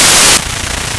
staticshort.wav